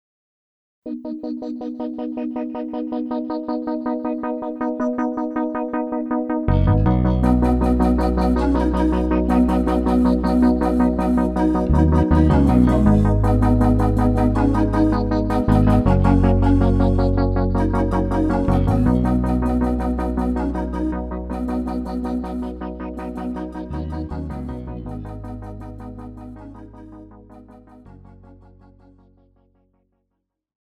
KARAOKE/FORMÁT:
Žánr: Rock
BPM: 160
Key: H
MP3 ukázka s melodickou linkou